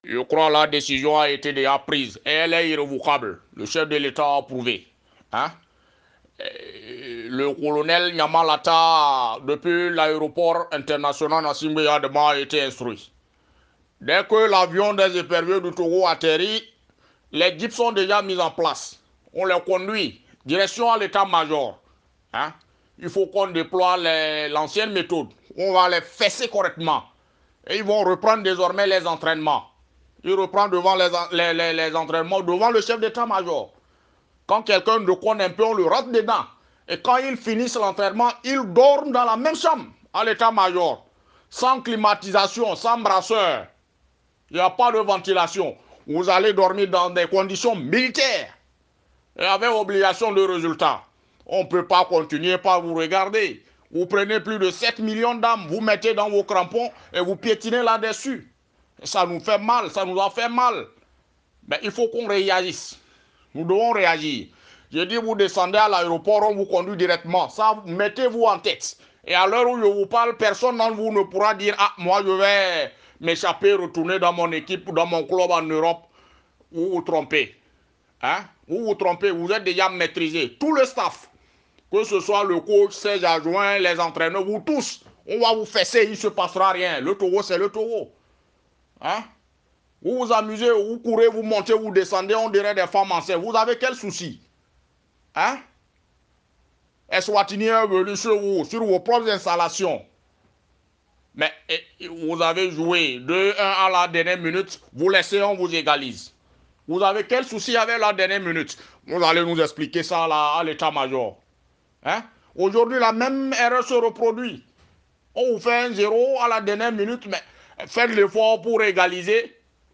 Cette situation désole énormément de Togolais y compris les humoristes qui n’hésitent pas à simuler l’utilisation des méthodes fortes pour permettre aux Éperviers de remonter la pente.